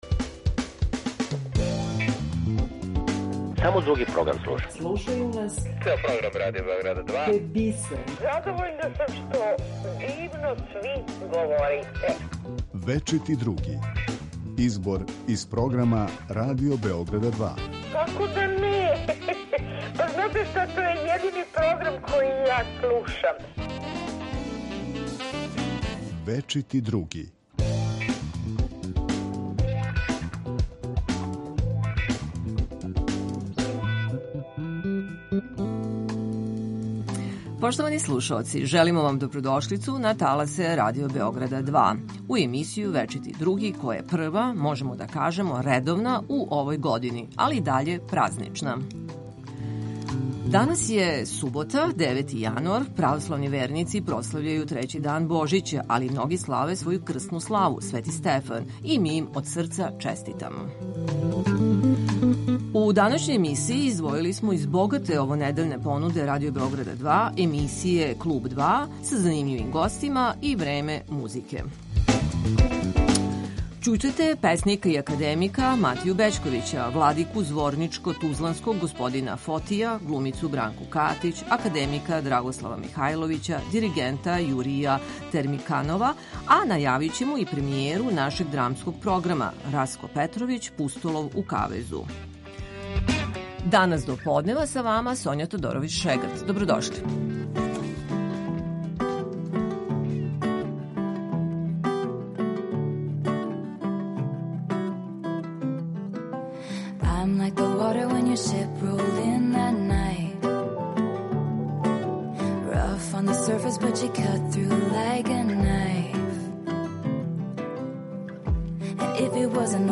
Чућете песника и академика Матију Бећковића, владику зворничко-тузланског Фотија, глумицу Бранку Катић, академика Драгослава Михаиловића, диригента Јурија Темирканова, а најавићемо и премијеру нашег драмског програма ‒ Растко Петровић: „Пустолов у кавезу".